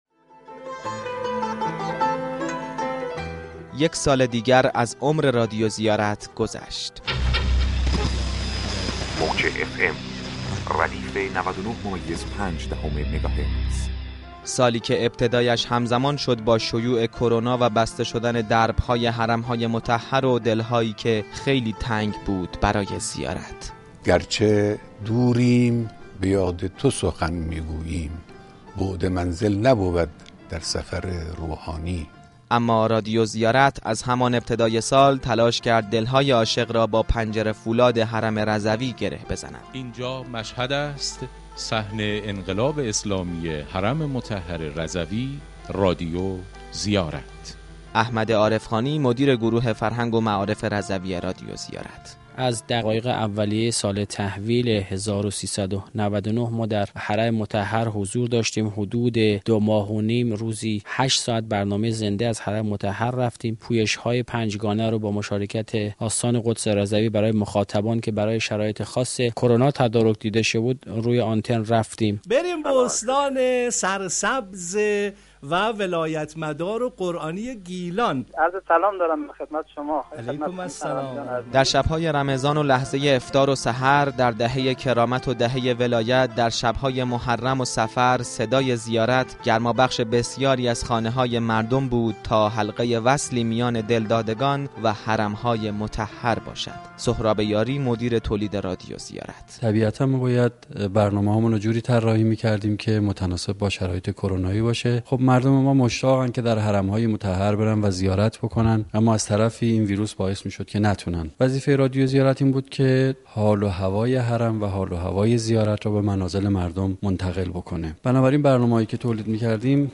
گزارشی از عملکرد این رادیو در حیطه برنامه سازی در یکسال گذشته بشونیم.